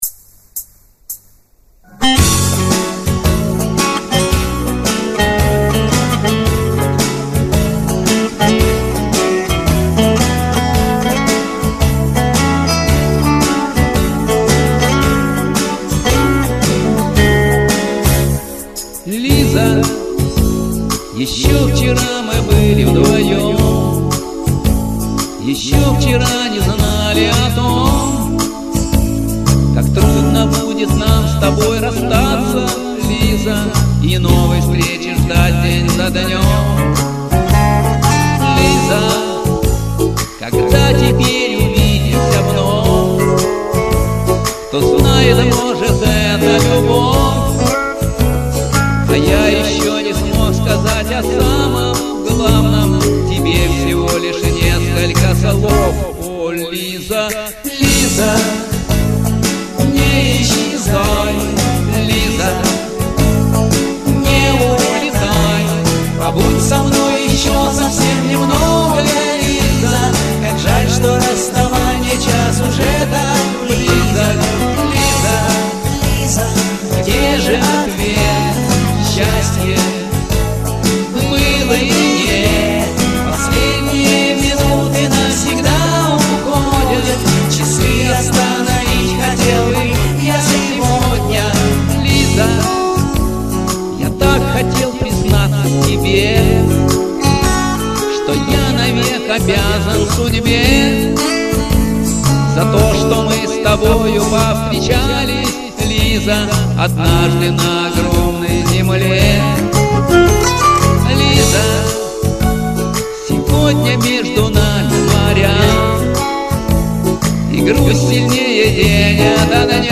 Так молодо и красиво звучит голос...